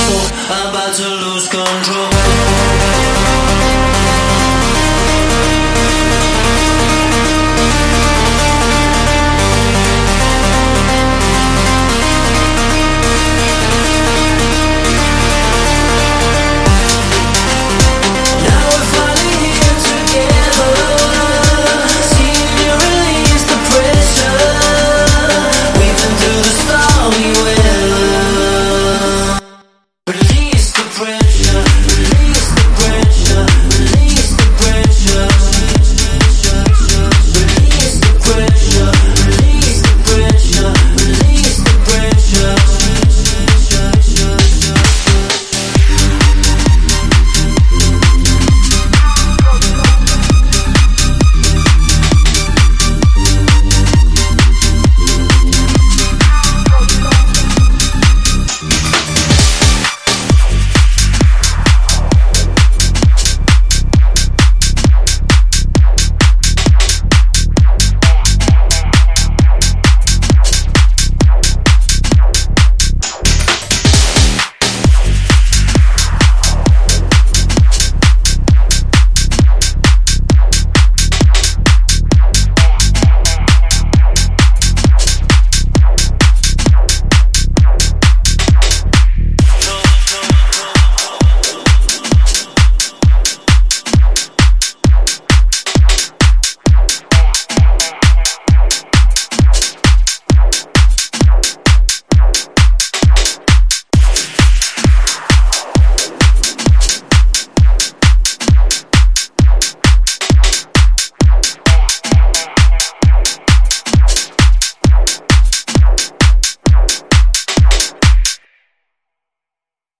132 bpm and in G Minor.